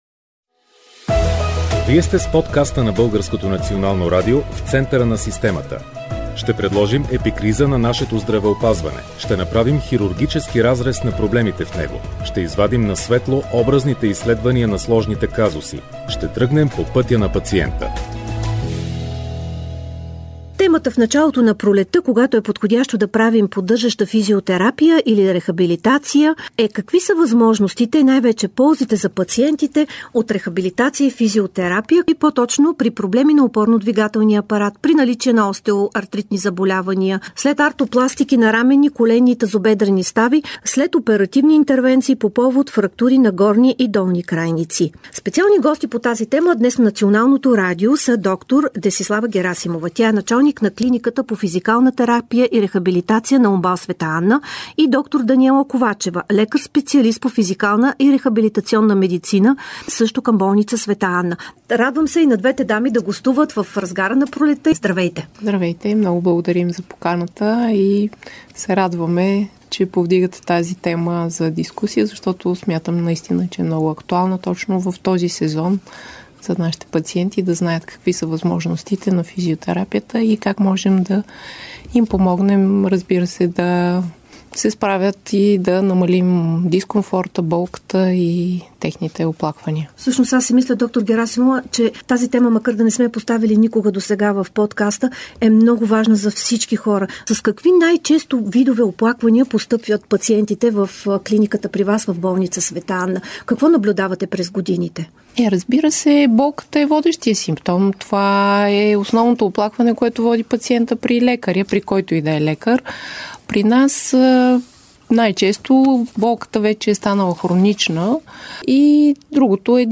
В този епизод на подкаста физикални лекари от болница "Света Анна" в столицата разказват за Физиотерапия и рехабилитация